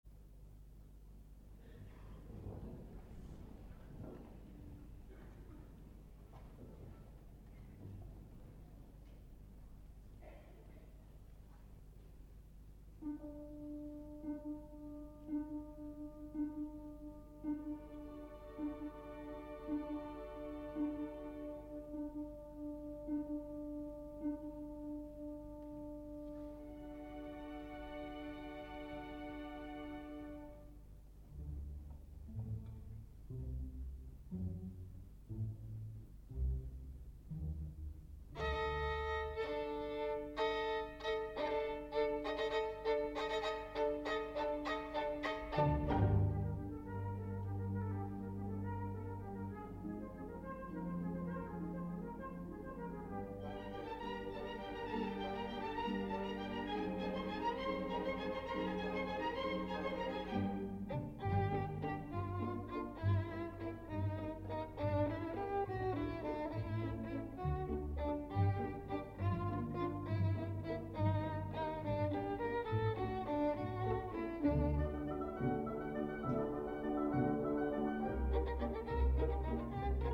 03.12.1986 Konzert des Collegium musicum
Genre: Classical.